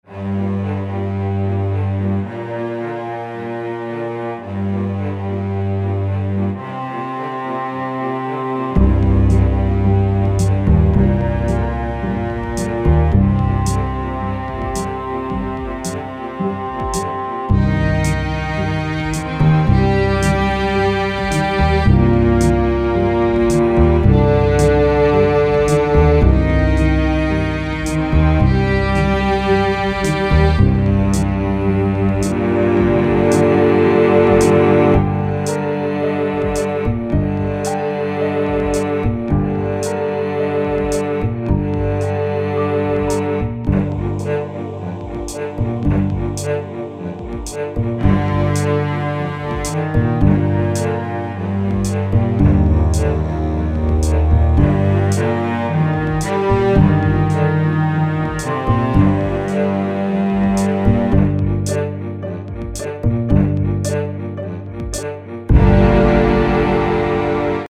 Short music project for a MIDI class I did for fun.